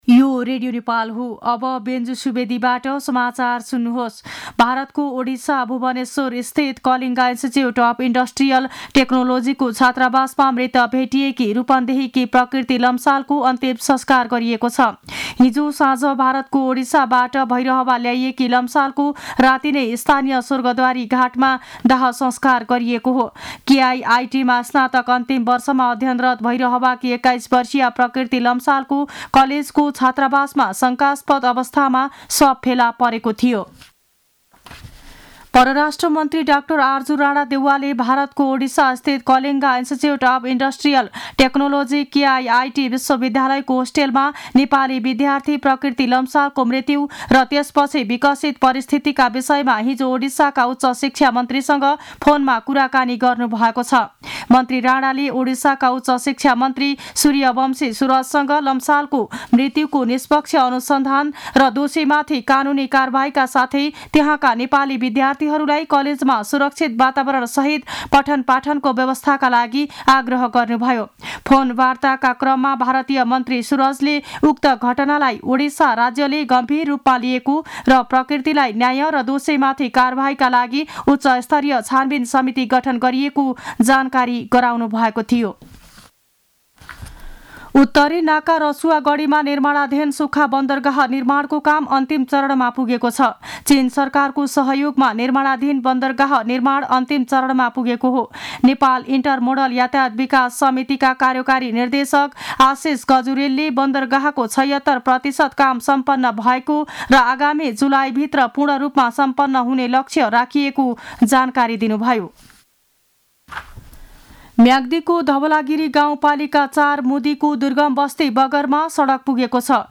मध्यान्ह १२ बजेको नेपाली समाचार : ९ फागुन , २०८१